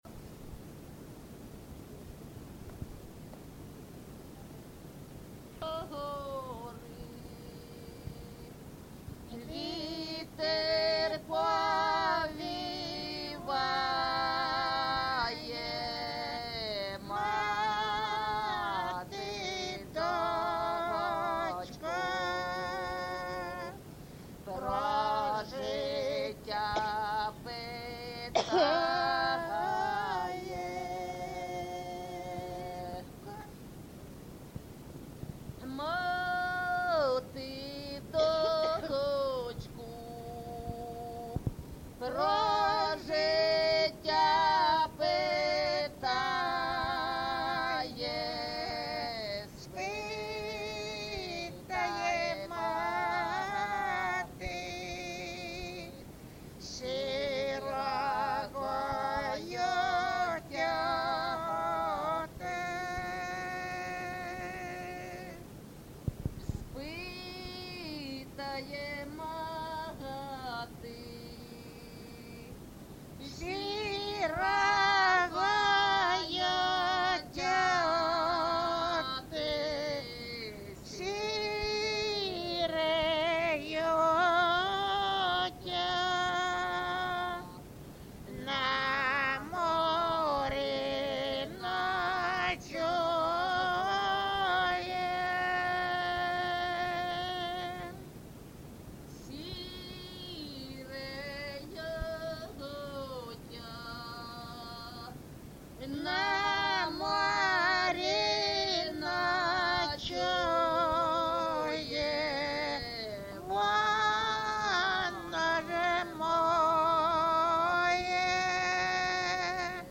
ЖанрПісні з особистого та родинного життя
Місце записус-ще Калинівка, Бахмутський район, Донецька обл., Україна, Слобожанщина